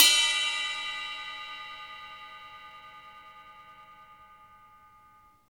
Index of /90_sSampleCDs/Roland L-CDX-01/CYM_Rides 1/CYM_Ride menu